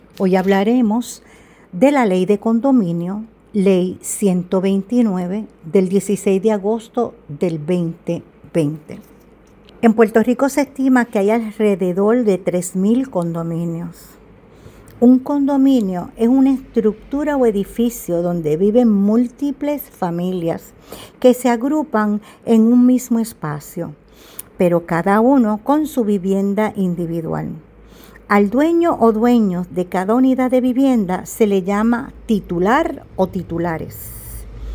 PODCAST: REPASO DE BIENES RAÍCES DE PUERTO RICOUna gran herramienta de estudio pensando en ti.